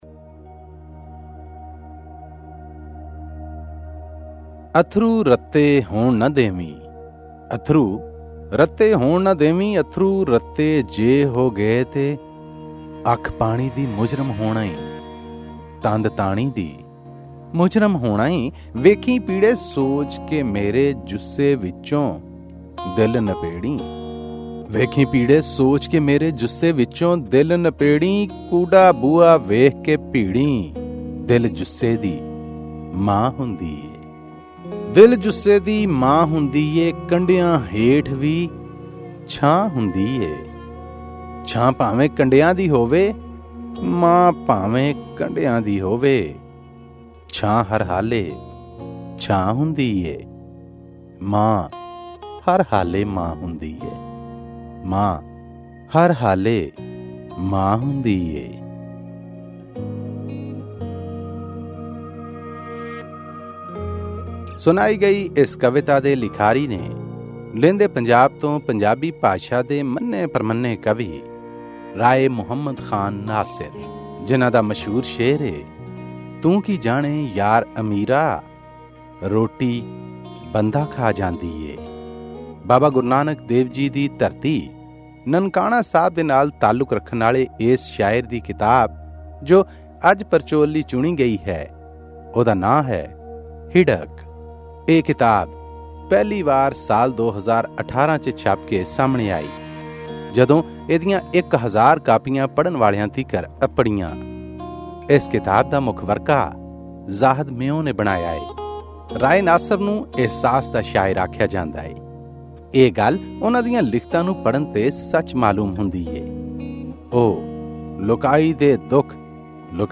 Pakistani Punjabi poetry book review